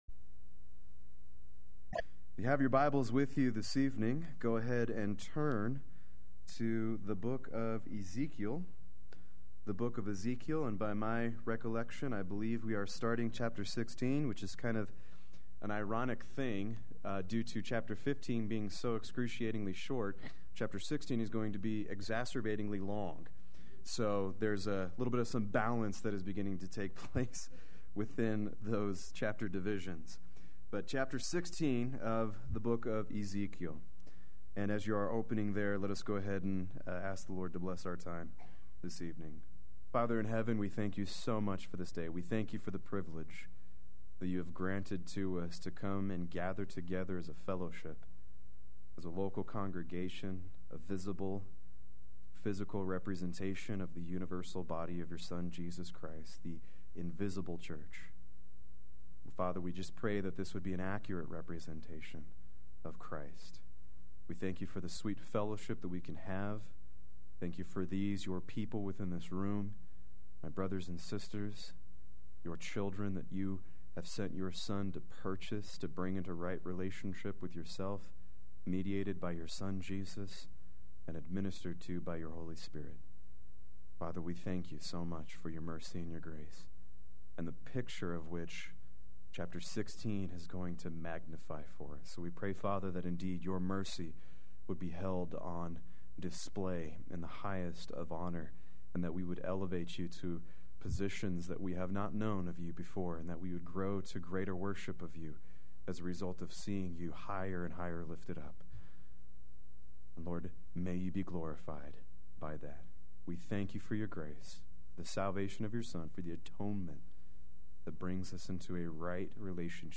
Play Sermon Get HCF Teaching Automatically.
Chapter 16 Wednesday Worship